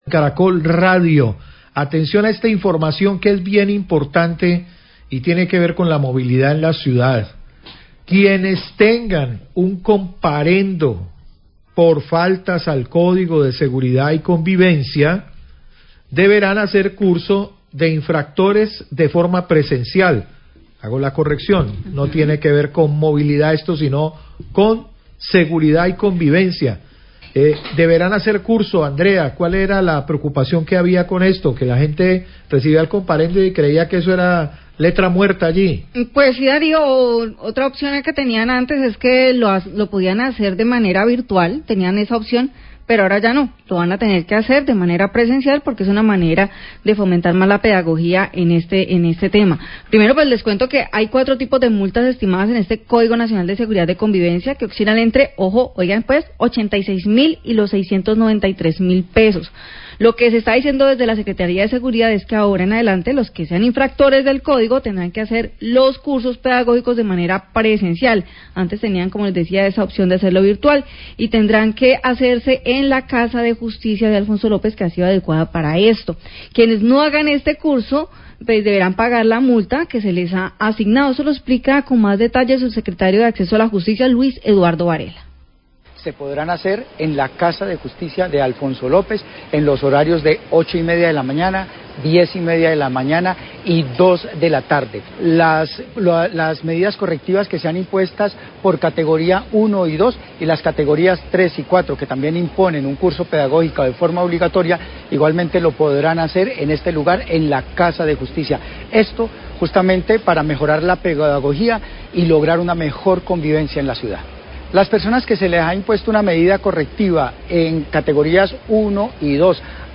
Radio
Quienes tengan comparendos pedagógicos por faltas al código de seguridad y convivencia, deberán hacer un curso presencial y ya no vitual como se acostumbraba anteriormente, informó la Secretaría de Seguridad de Cali. Declaraciones del Subsecretario de Acceso a la Justicia, Luis Eduardo Varela.